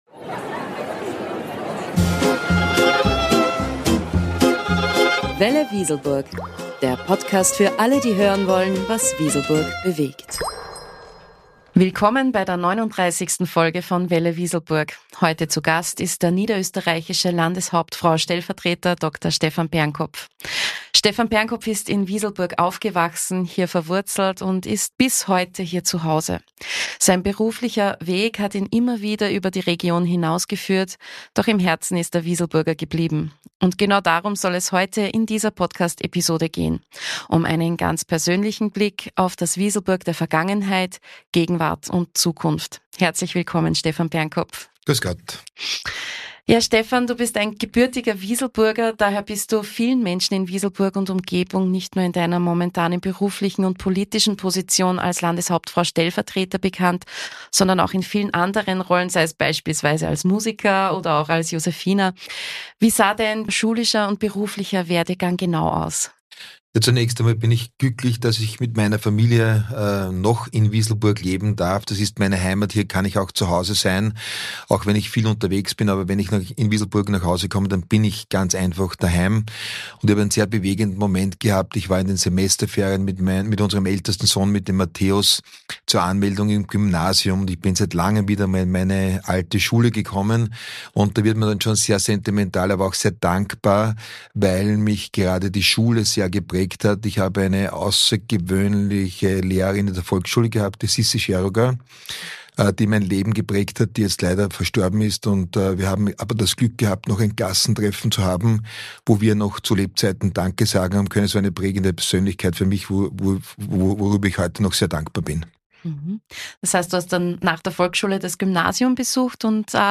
Folge 39 | Ein persönliches Gespräch mit Dr. Stephan Pernkopf: Landeshauptfrau-Stellvertreter und stolzer Wieselburger ~ Welle.Wieselburg Podcast